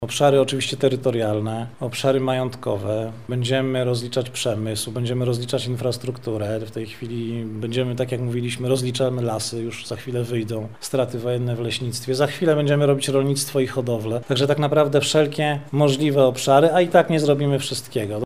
Ten temat poruszono podczas konferencji prasowej „Lublin. Wschodni wektor pamięci” w Muzeum Czechowicza.
O obszarach, które zostaną zbadane mówi Bartosz Gondek, dyrektor Instytutu Strat Wojennych: